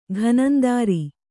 ♪ ghanandāri